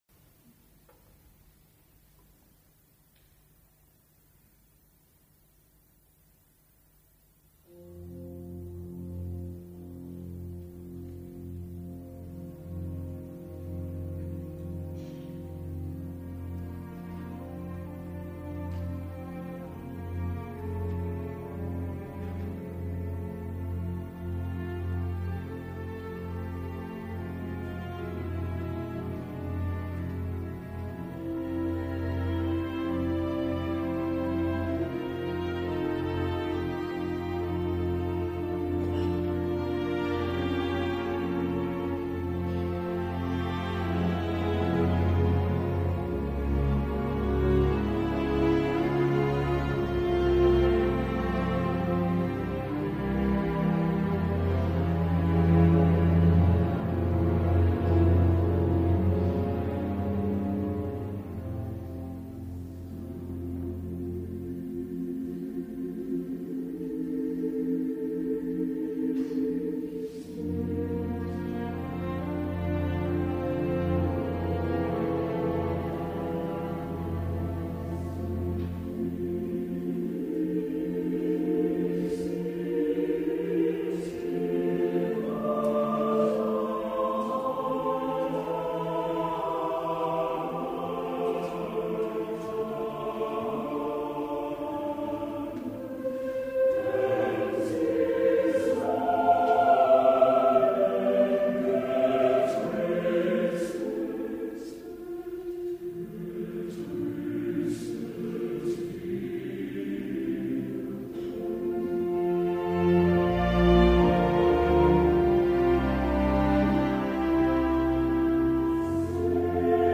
Juntament amb el Parsifal del que teniu a disposició el corresponent vídeo, Christian Thielemann i la Sächsische Staatskapelle Dresden, van protagonitzar en l’edició Pasqual del Festival de Salzburg el sempre colpidor Rèquiem Alemany de Johannes Brahms.
amb una direcció pausada, molt serena i elegant, detallista i espectacular en els grans moments
poderós Cor